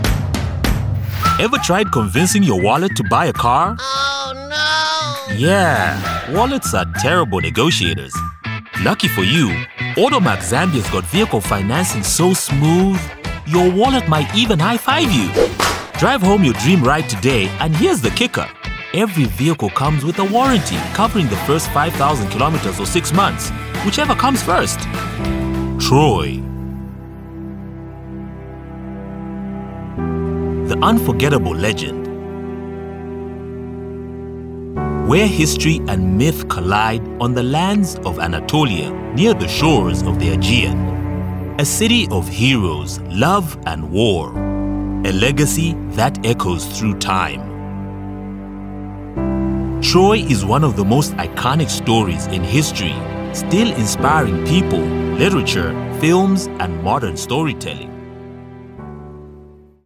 English (African)
Conversational
Authoritative
Warm